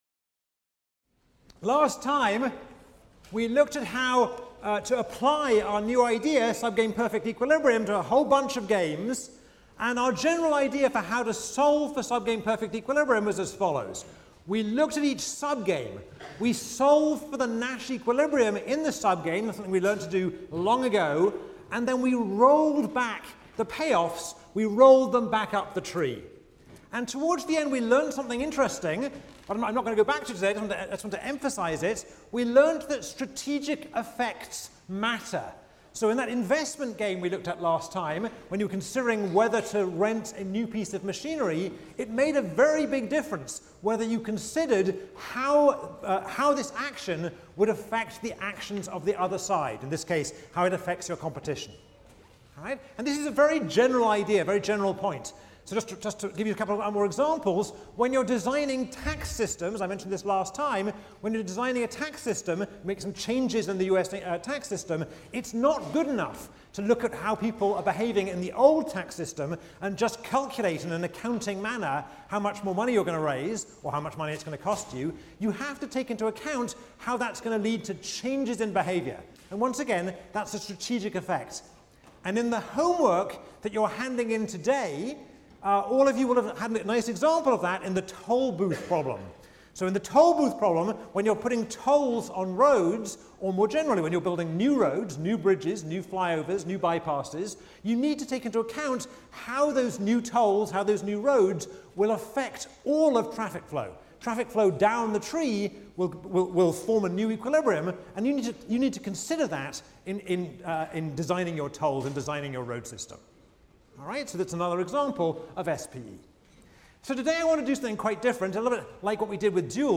ECON 159 - Lecture 20 - Subgame Perfect Equilibrium: Wars of Attrition | Open Yale Courses